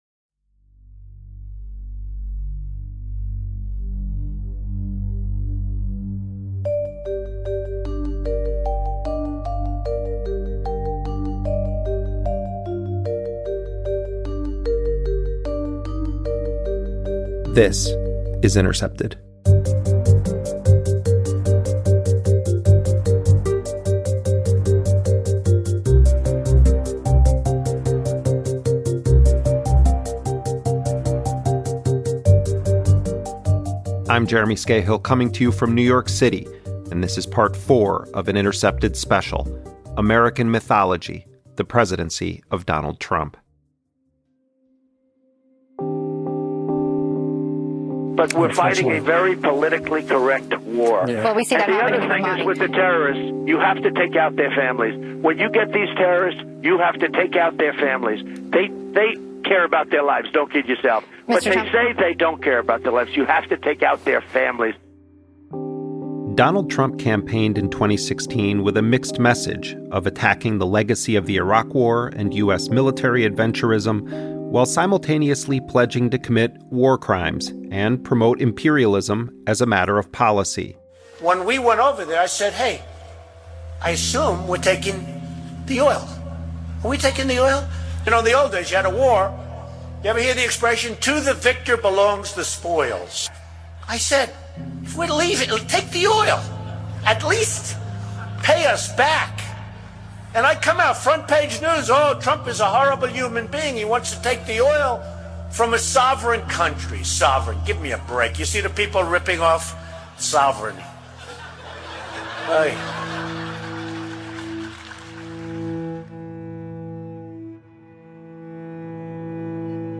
An Intercepted audio documentary series offers a comprehensive analytical history of the Trump presidency.